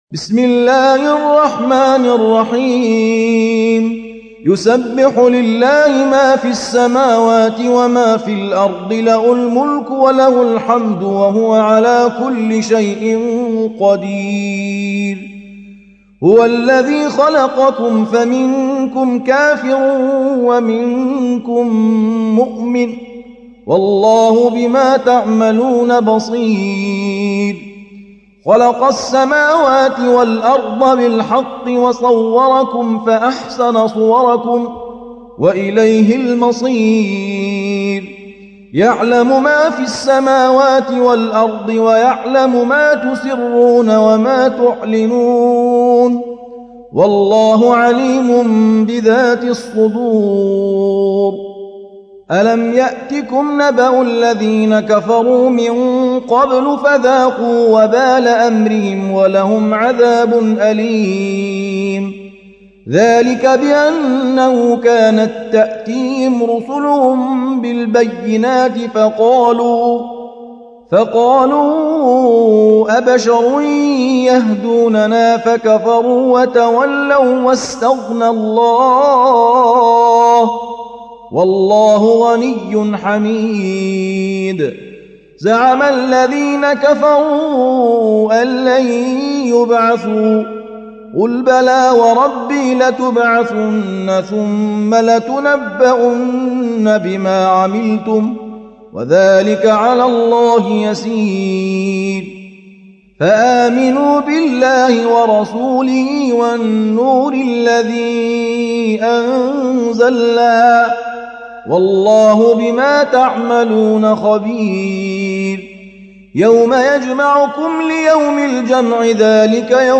التصنيف: تلاوات مرتلة